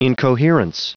Prononciation du mot incoherence en anglais (fichier audio)
Prononciation du mot : incoherence